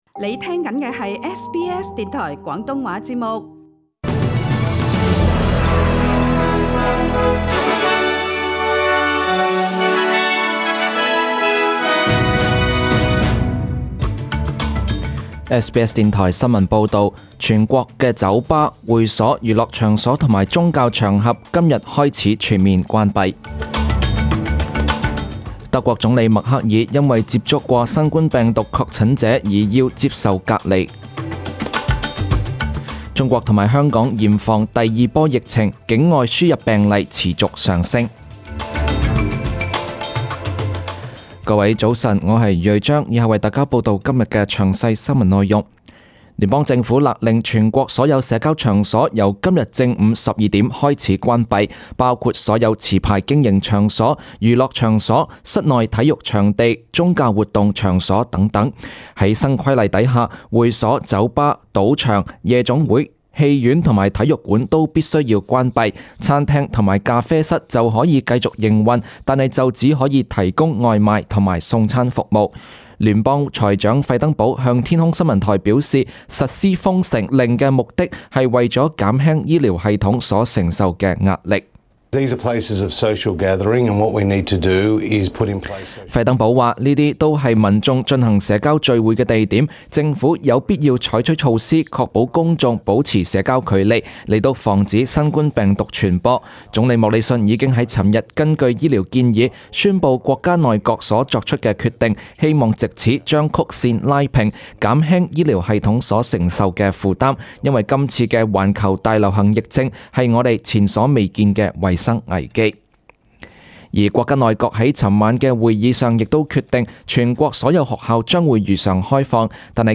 请收听本台为大家准备的详尽早晨新闻。
SBS 廣東話節目中文新聞 Source: SBS Cantonese